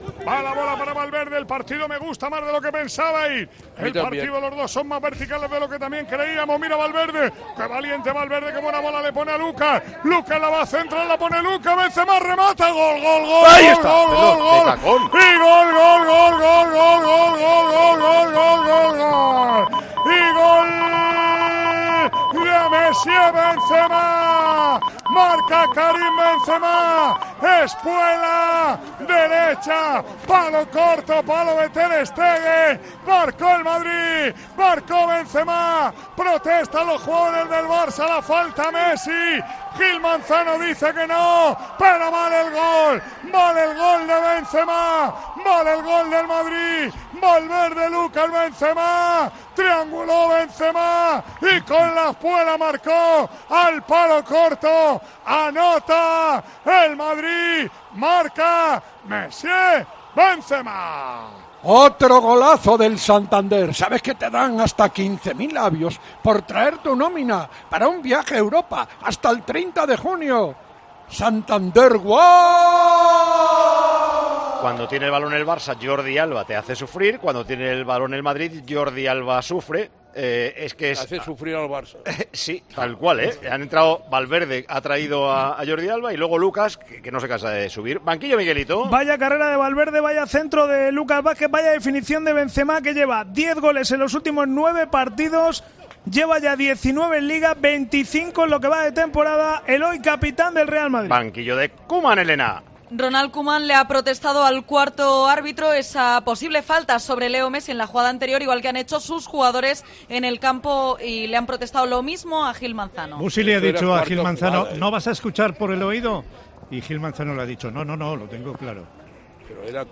Así sonaron los goles de la victoria del Real Madrid en el Clásico ante el Fútbol Club Barcelona por 2-1.